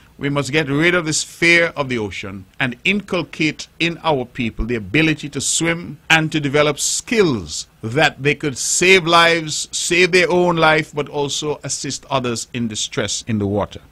Premier of Nevis, Hon. Mark Brantley as he spoke on the barriers being overcome with the help of the World Academy of Safety and Health (WASH).